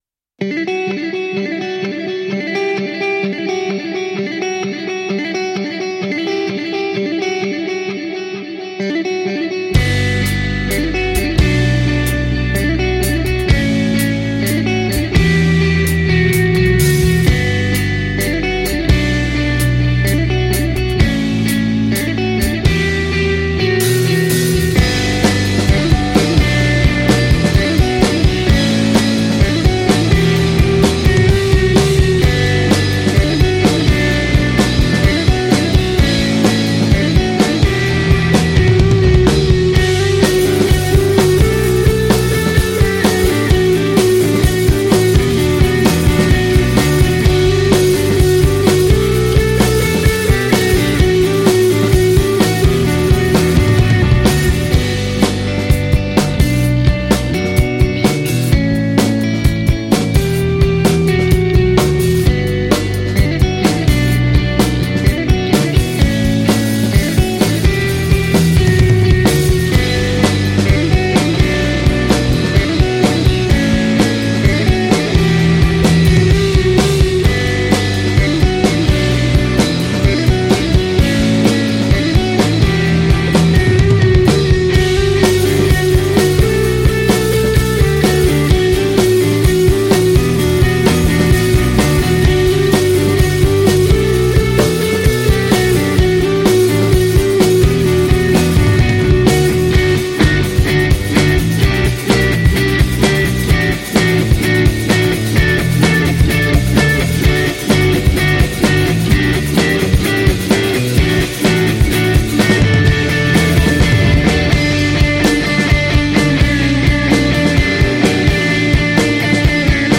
Гитара, бас и барабаны.
Каждый трек пишем одним дублем.
Нам хочется честной и сырой записи.